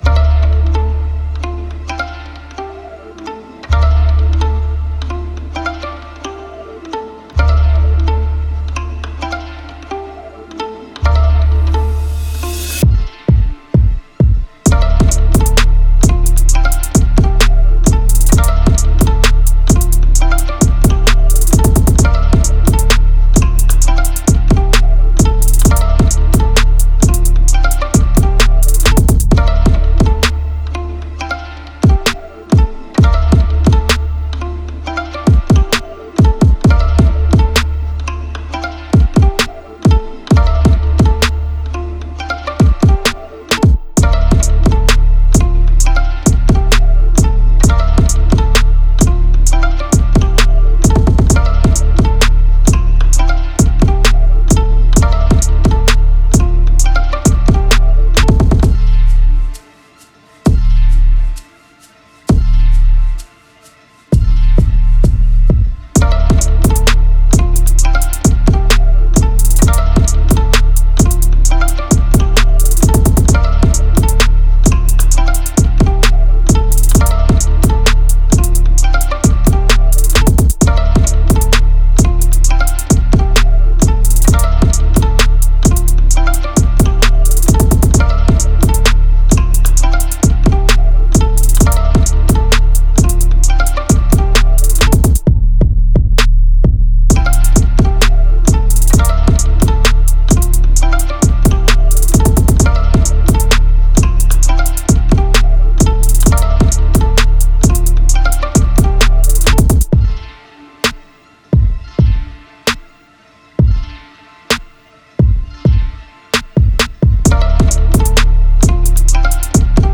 BPM131
NOTADm
MOODChill
GÉNEROHip-Hop